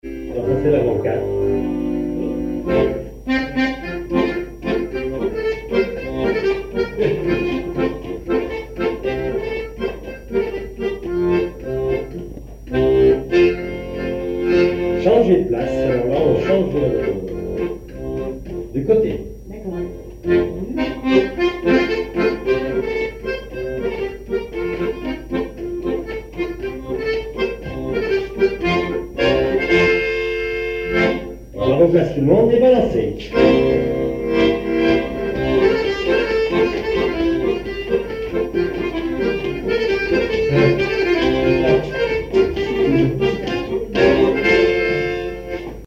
danse : quadrille : avant-quatre
répertoire à l'accordéon diatonique
Pièce musicale inédite